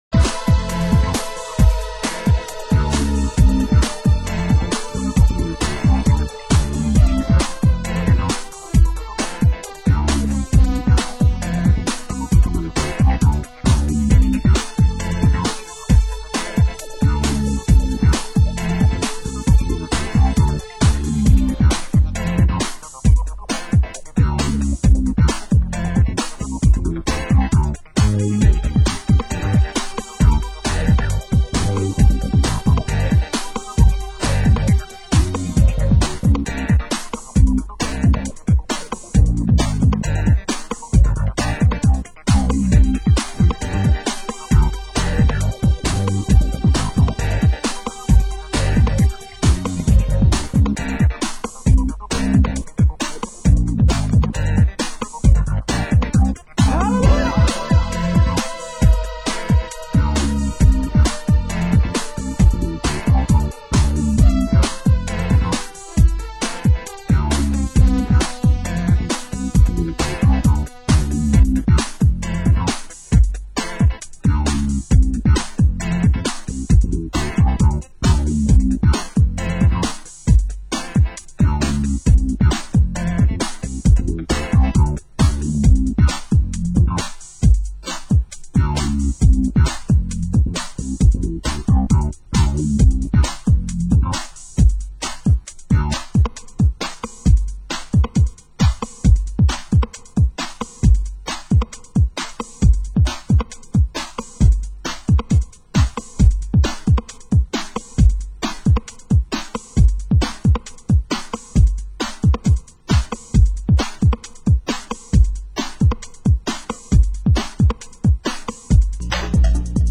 Genre: Ghetto Tech